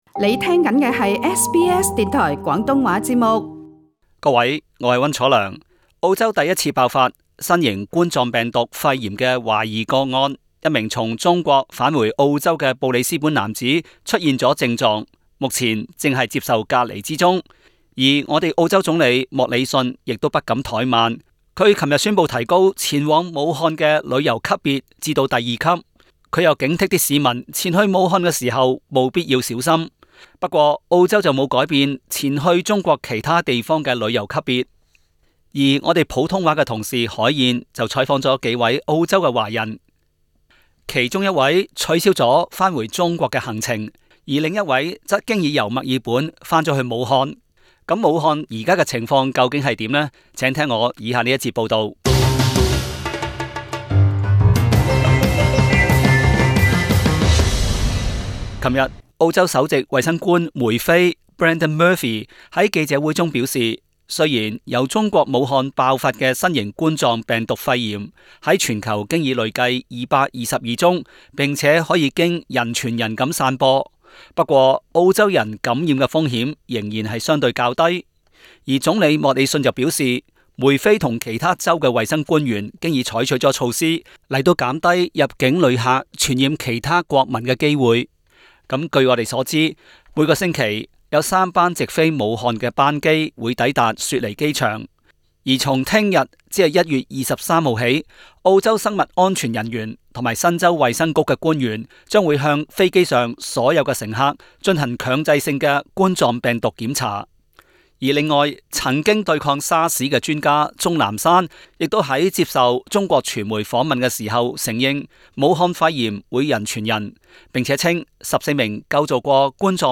另外，本台普通話組亦訪問了一位於月初返回武漢的女子，讓聽眾可了解武漢目前的情況。